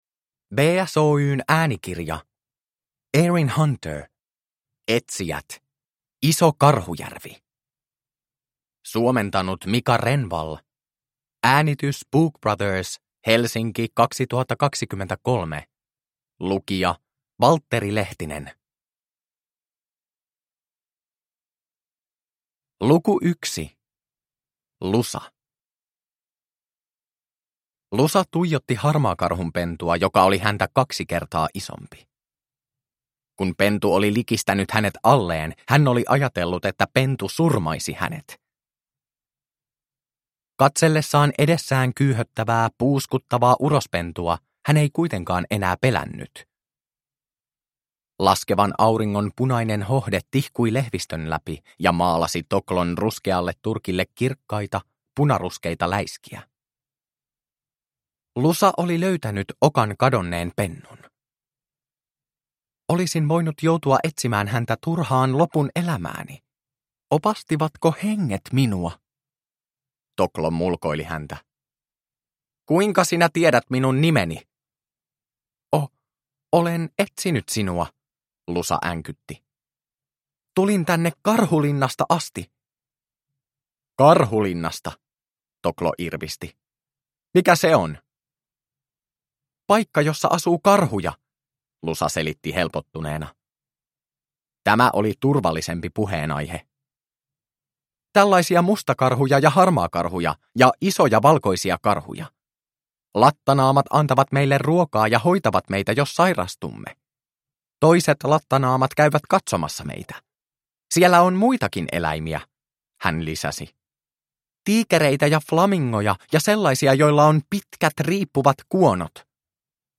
Etsijät: Iso Karhujärvi – Ljudbok – Laddas ner